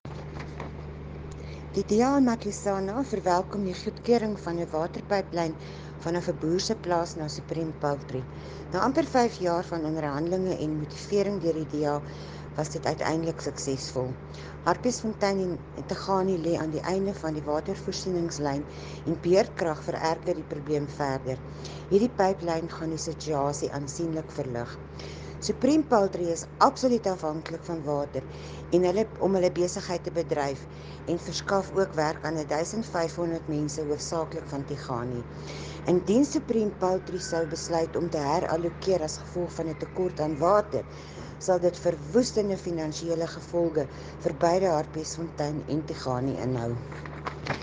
Note to Broadcasters: Please find linked soundbites in